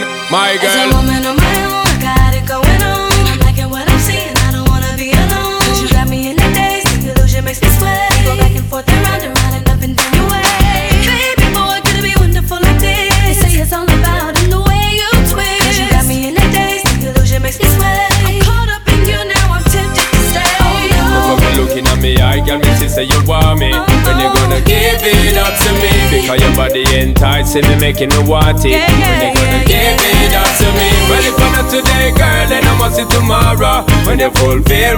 Жанр: Хип-Хоп / Рэп / R&B / Регги / Соул